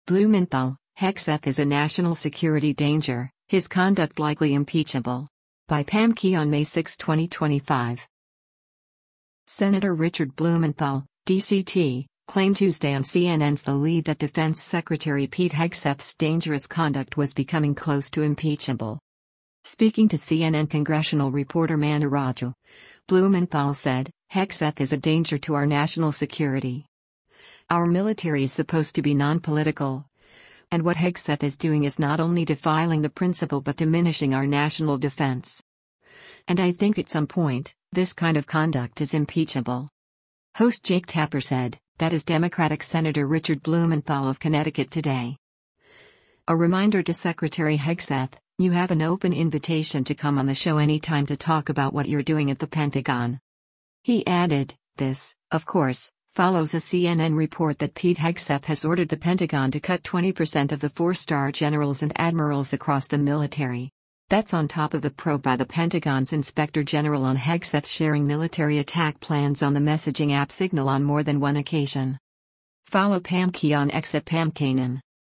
Senator Richard Blumenthal (D-CT) claimed Tuesday on CNN’s “The Lead” that Defense Secretary Pete Hegseth’s dangerous conduct was becoming close to impeachable.